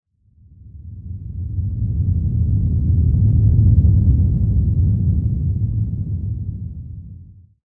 sounds / ambient / cave
cave11.ogg